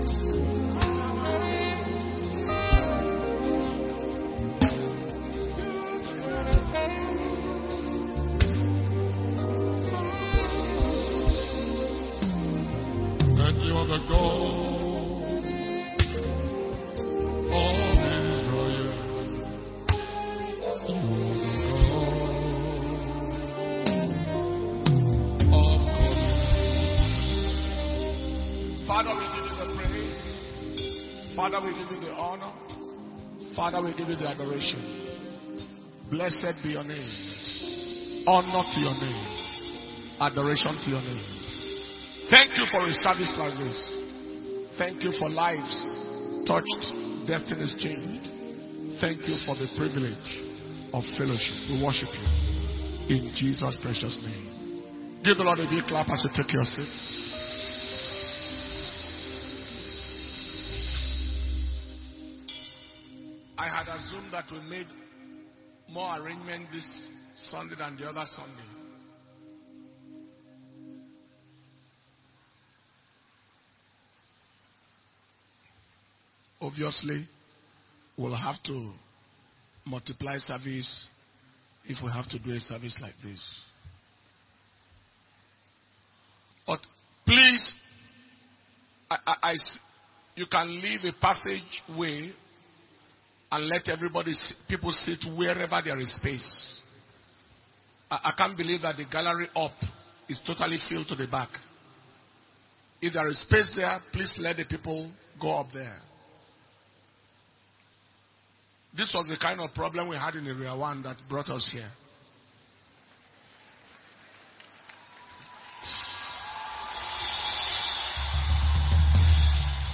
June 2023 Blessing Sunday Service